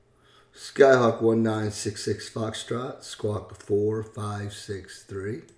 Aviation Radio Calls